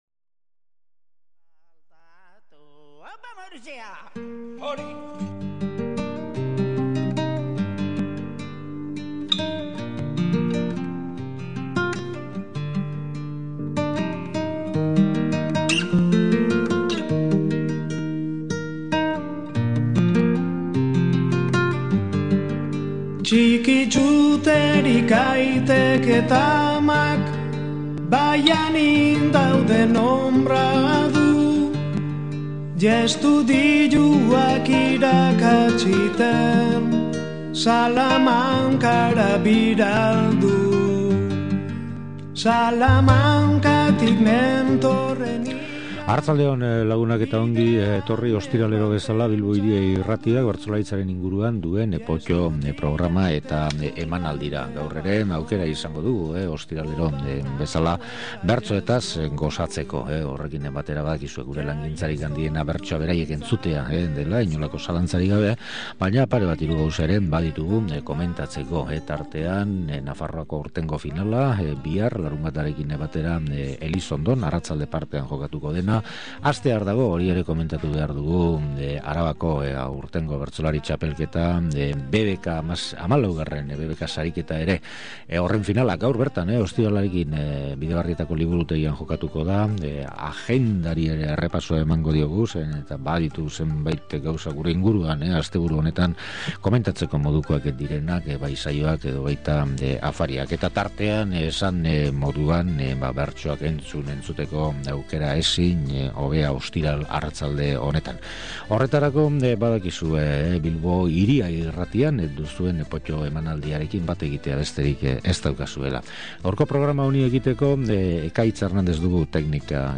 Entzungai jartzen dugun saio honen funtsa hitz laburrez azaldu beharko bagenu, bi zati dituela esango genuke: batetik ezagunak eta maiz entzunak ditugun bertsolariei dagokiena eta, bestetik, ezezagun samarrak eta gutxiegitan entzunak ditugun bertsolariei dagokiena. Lehenengo atalean, Maialen Lujanbiok eta Sustrai Colinak 2002an Bilboko Kafe Antzokian egin zuten eta elkarren artean egindako lehena izan zen saioa ekarri dugu gogora. 2005eko Bertso Egunera ere jo dugu, egun hartan Xabier Amurizak eta Andoni Egañak eginiko saioa oroitarazteko.